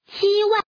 Index of /client/common_mahjong_tianjin/mahjonghntj/update/1307/res/sfx/woman/